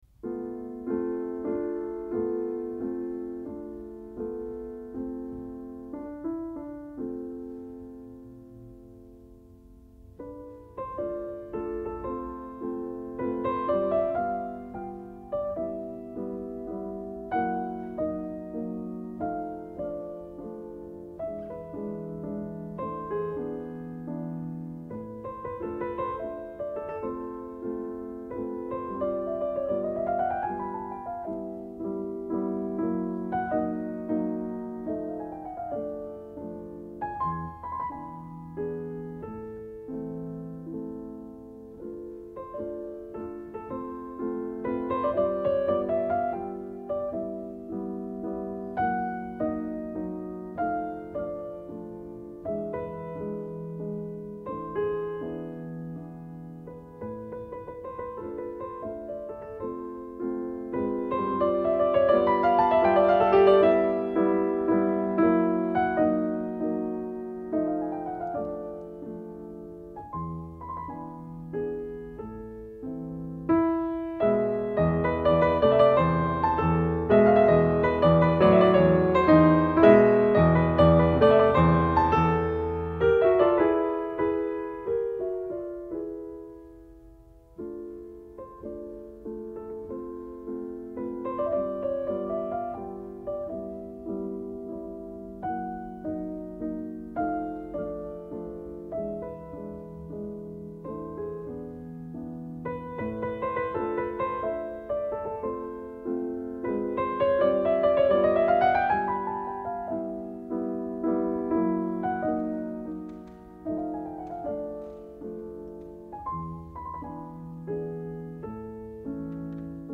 Arthur Rubinstein 1965 – Frédéric Chopin mazurka en la mineur Opus 17 n°4